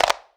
• Clean Clap One Shot E Key 15.wav
Royality free hand clap sound - kick tuned to the E note. Loudest frequency: 1564Hz
clean-clap-one-shot-e-key-15-CHG.wav